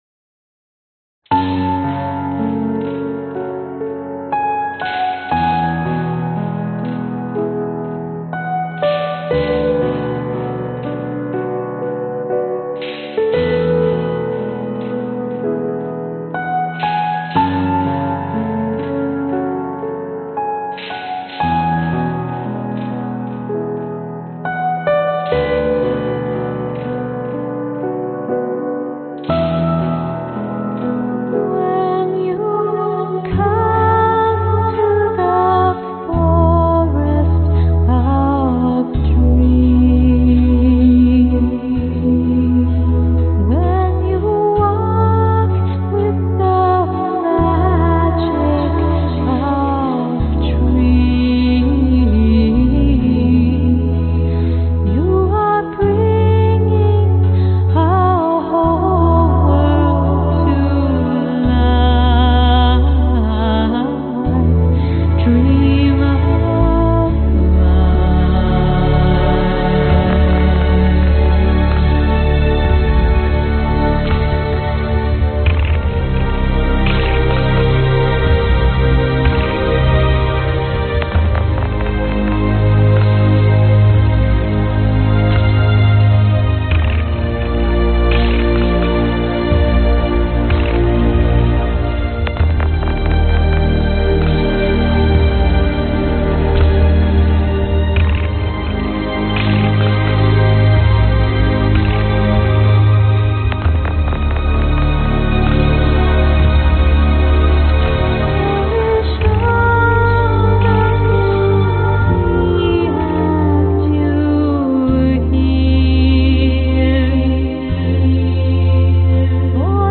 描述：big strings playing minor chords
标签： ambient chill downtempo orchestral violin
声道立体声